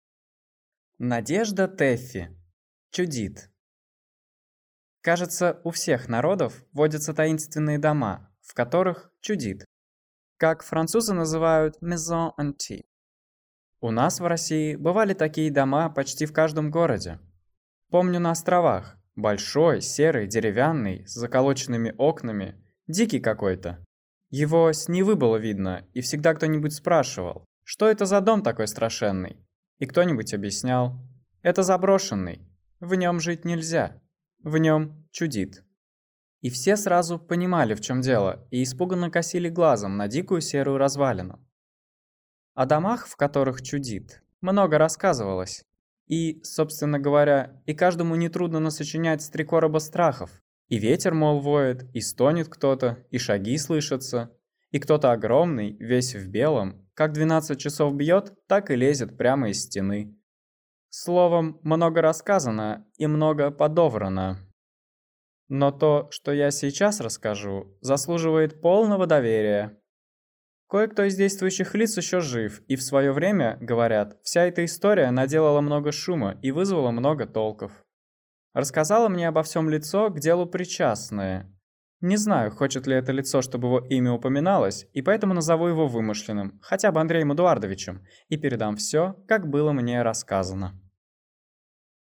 Аудиокнига «Чудит» | Библиотека аудиокниг